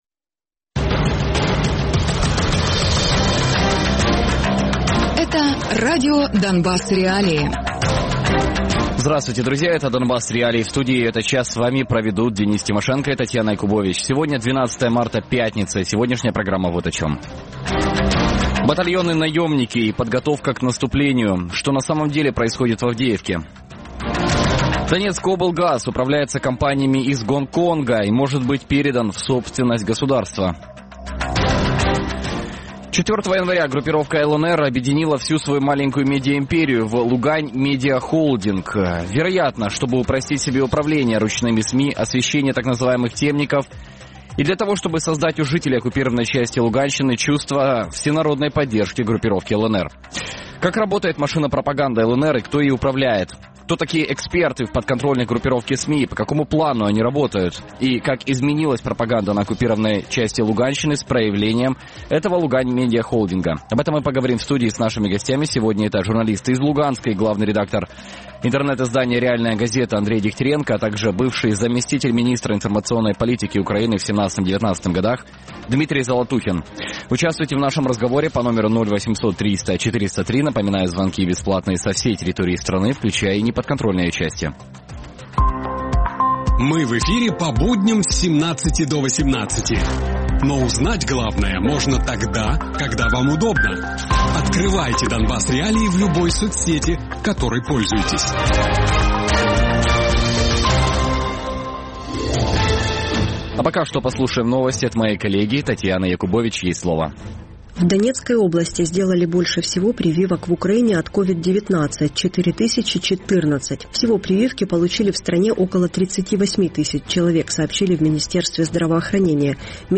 Гості програми радіо Донбас.Реалії